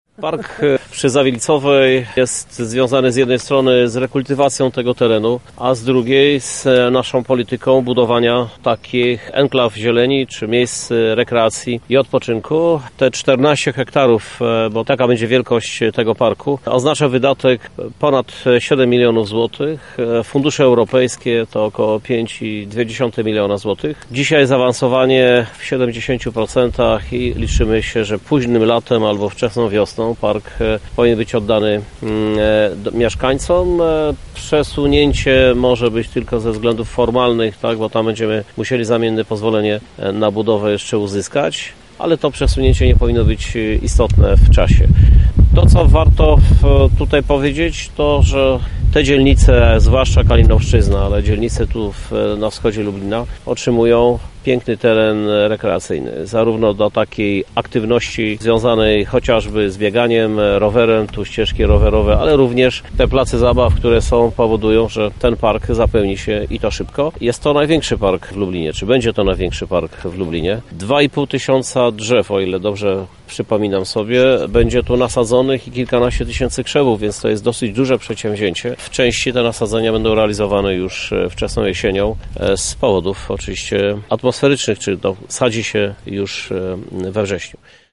• Takie miejsce było tu potrzebne – mówi Krzysztof Żuk, prezydent Lublina.